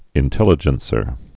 (ĭn-tĕlə-jən-sər, -jĕn-)